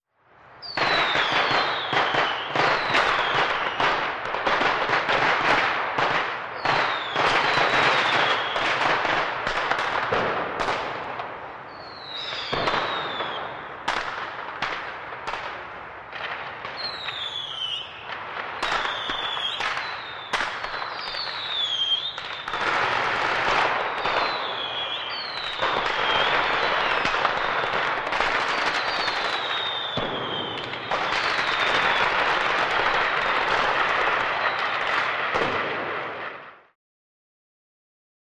Fireworks; Fireworks & Skyrockets with Reverb From Buildings; ( 2 ) Fuse Burning.